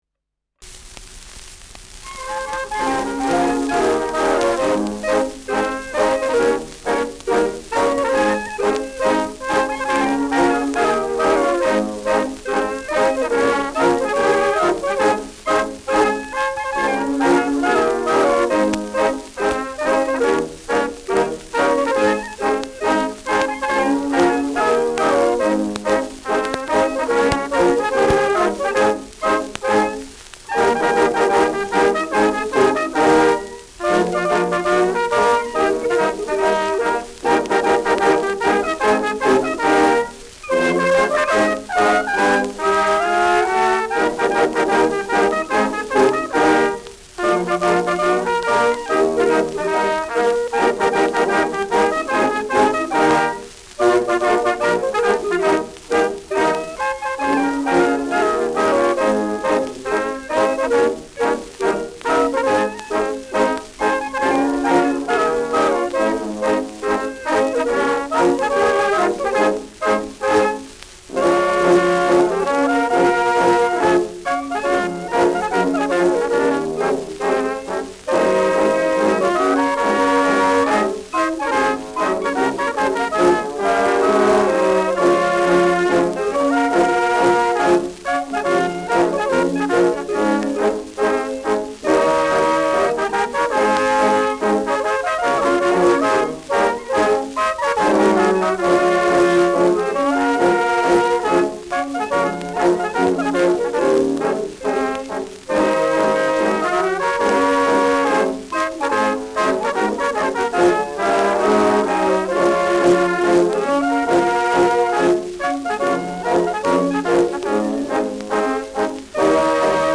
Schellackplatte
[Nürnberg] (Aufnahmeort)